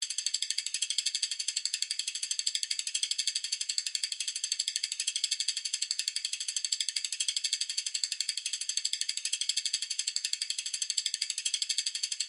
На этой странице собраны звуки шестерёнок разного типа: от мягкого перекатывания до резкого металлического скрежета.
Шорох вращающейся шестерёнки